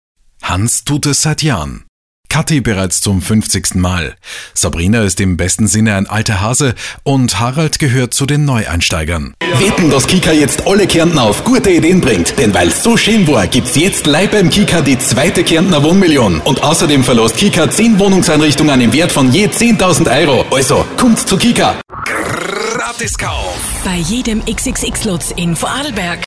Professionelle Sprecher und Sprecherinnen
Österreichisch
Männlich